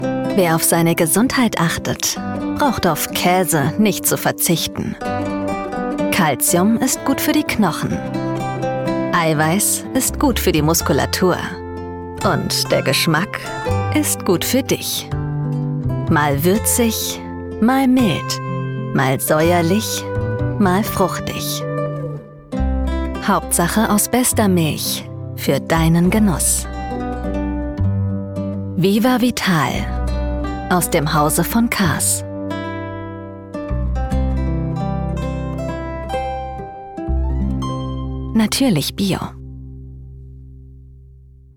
dunkel, sonor, souverän, markant, sehr variabel
Werbung Käse sanft und fröhlich
Commercial (Werbung)